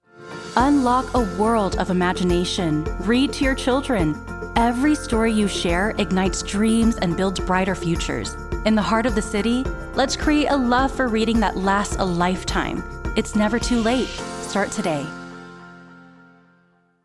expressive female voice talent
Reading PSA